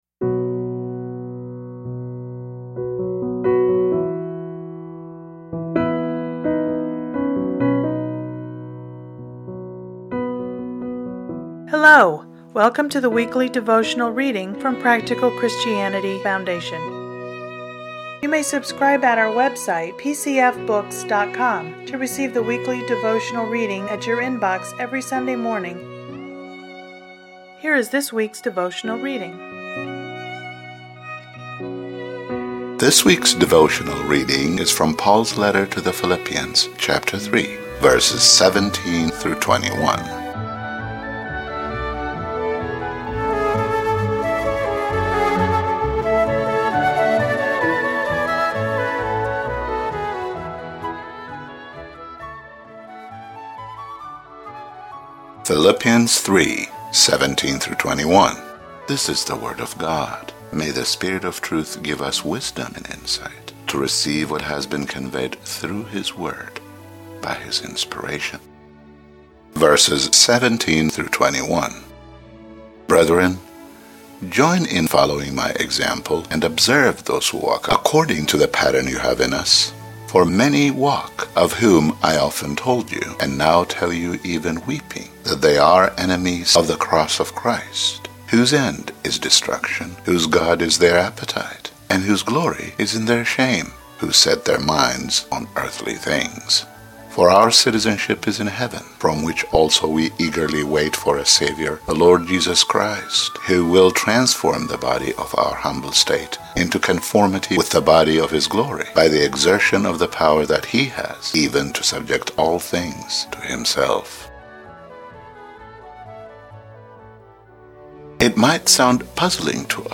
Listen to today's devotional commentary